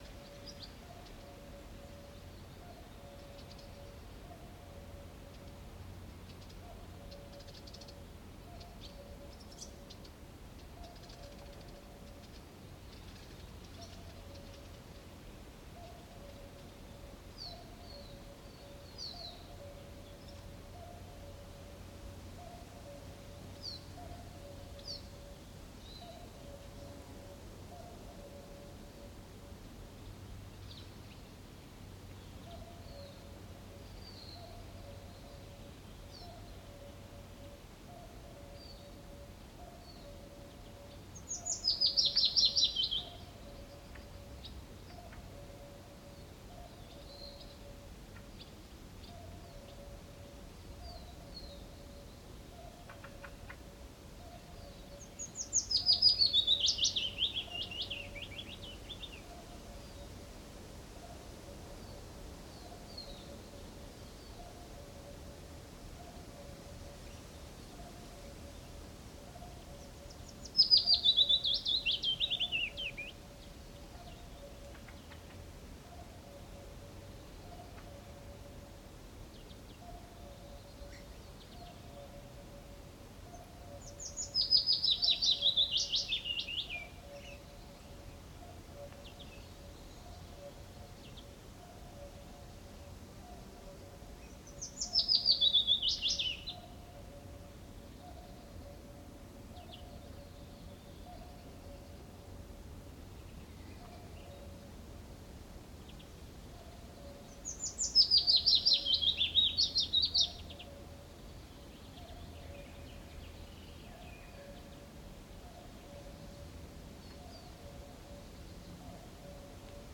Forest_Summer_LightWind_Cuckoo_Bees_BirdsCloseBy_Willowwarbler_Stereo.ogg